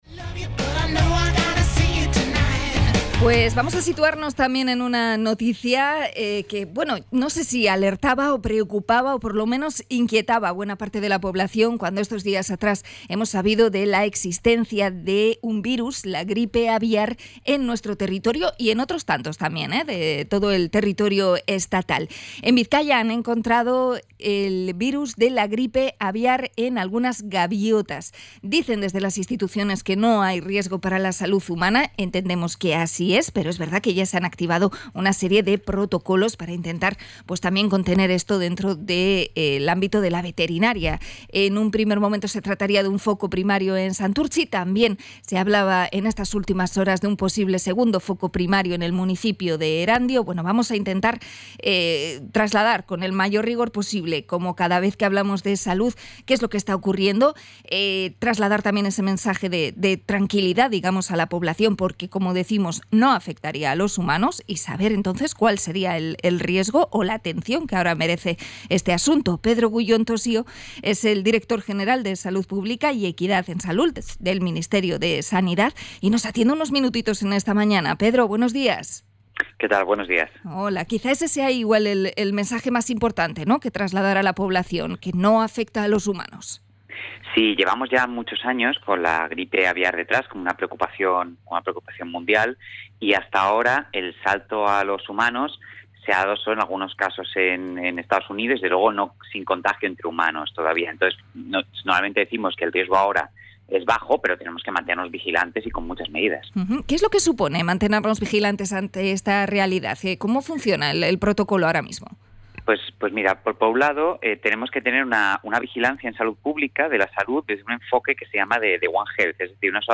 Entrevista a Salud Pública por los casos de gripe aviar
La entrevista completa puede escucharse en el programa EgunON Magazine.